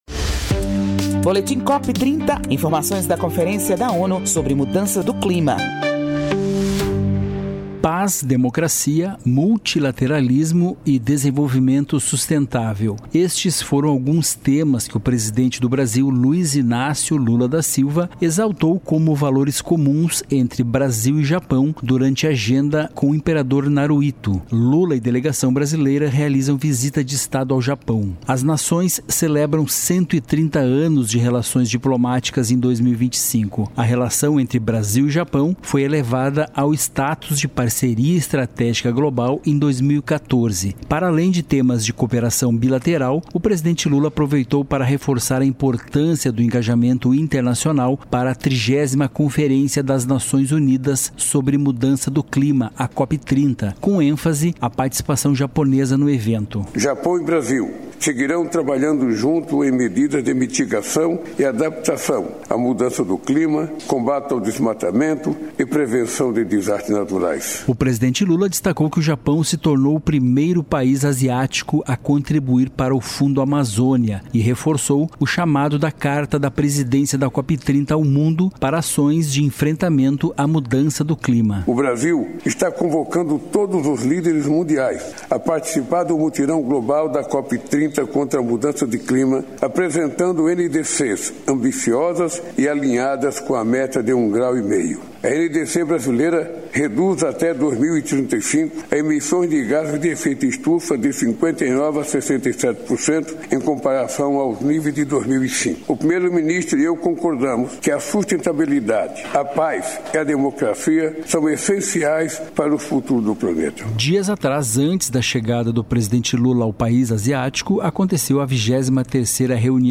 O presidente da COP30, André Corrêa do Lago, destaca em encontro em Brasília o papel de prefeitos e prefeitas no combate à mudança do clima. Segundo ele, as cidades são peças-chave para implementar soluções e alcançar metas do clima. Ouça a reportagem e saiba mais.